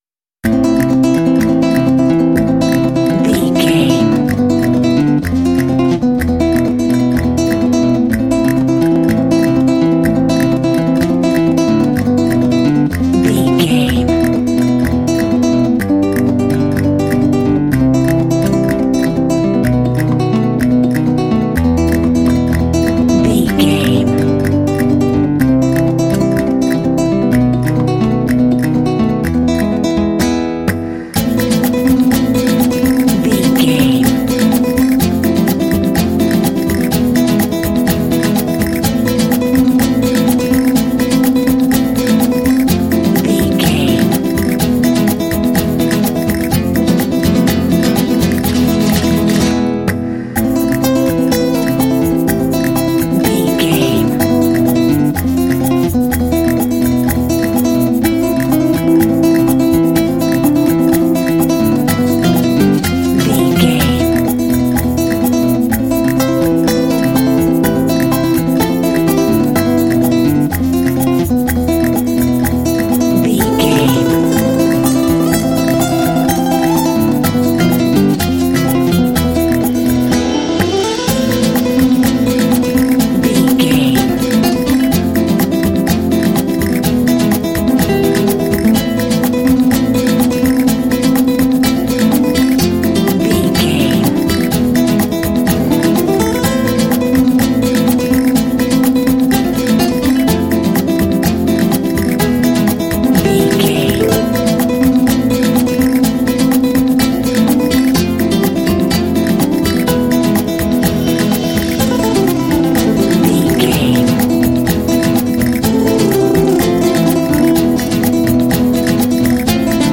Feel the summertime vibe.
Aeolian/Minor
lively
passionate
energetic
acoustic guitar
percussion